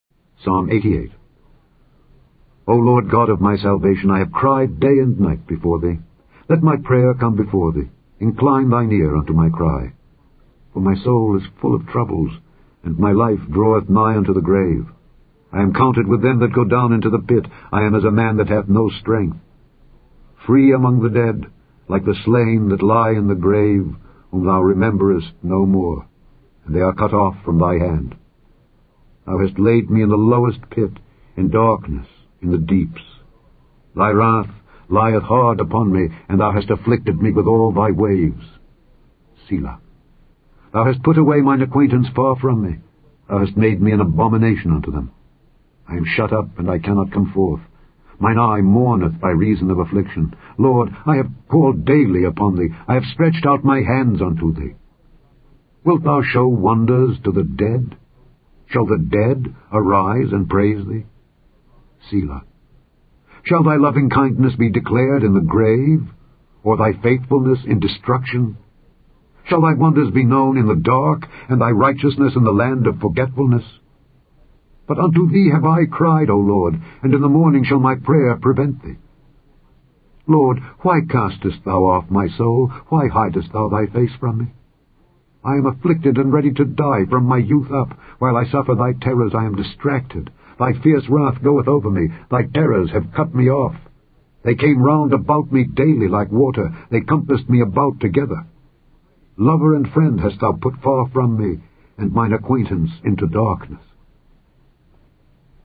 The Scourby Bible Readings are being aired with the permission of copyright owner Litchfield Associates.